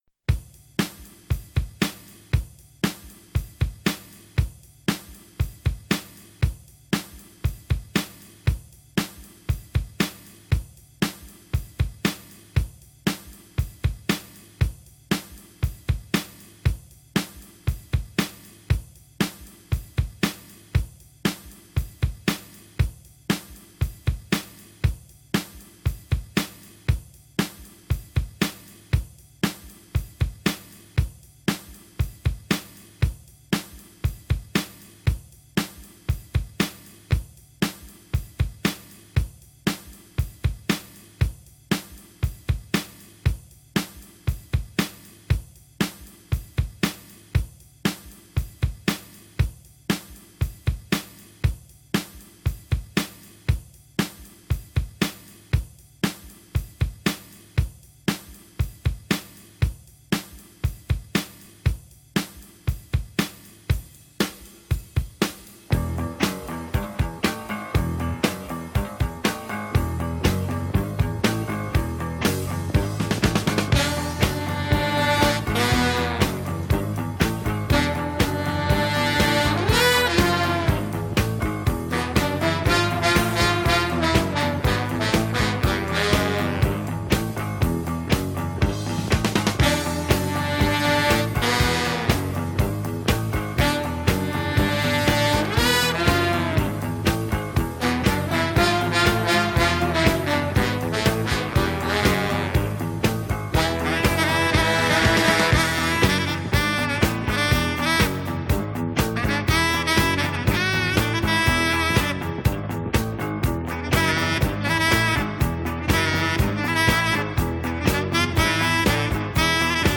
Fichier de travail du Tempo Triple-pas